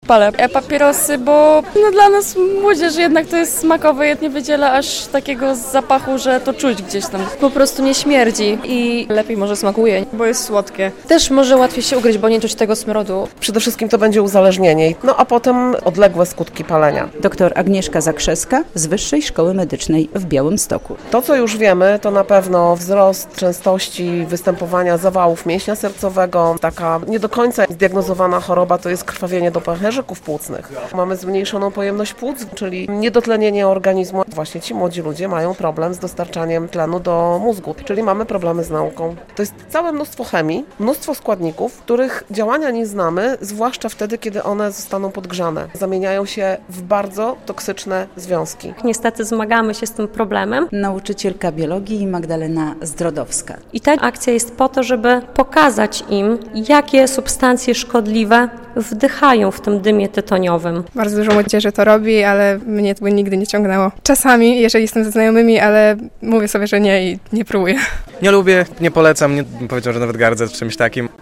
Groźne e-papierosy - relacja